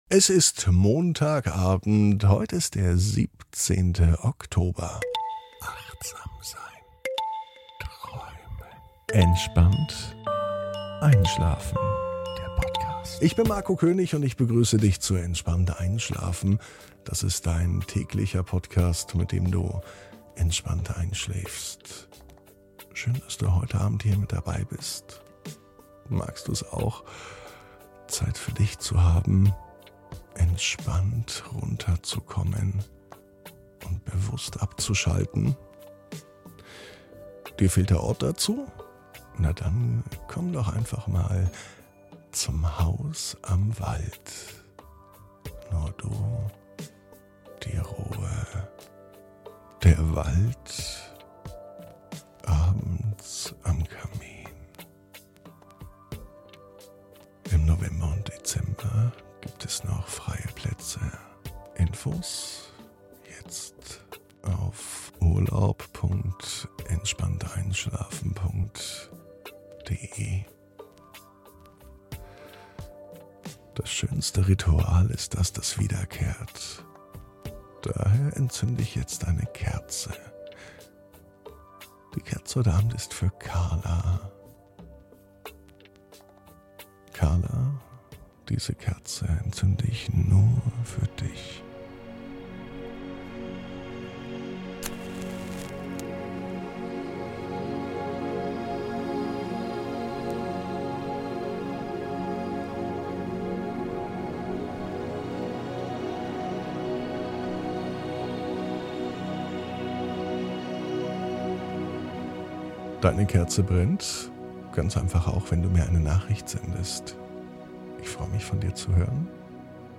(ohne Musik) Entspannt einschlafen am Montag, 17.10.22 ~ Entspannt einschlafen - Meditation & Achtsamkeit für die Nacht Podcast